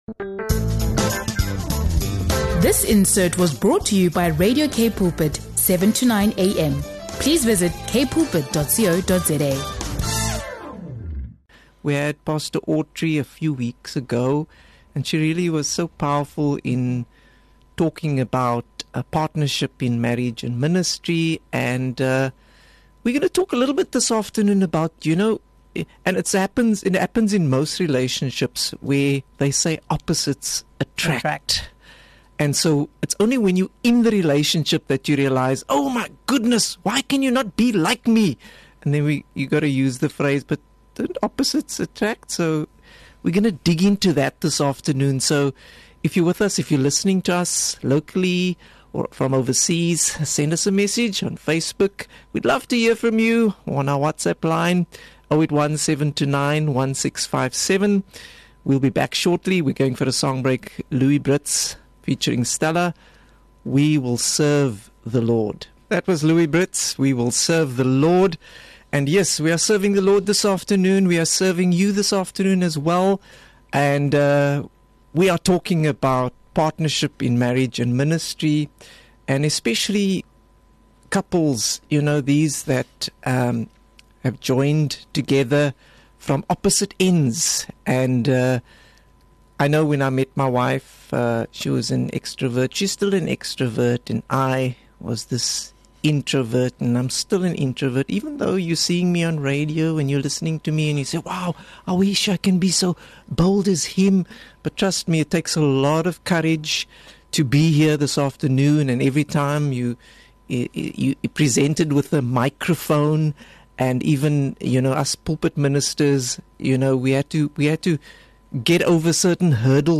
Join us as we unpack how to communicate through differences, give each other space to grow, and move from frustration to understanding—all while keeping God at the center. It's an honest, encouraging conversation for every couple walking in destiny together.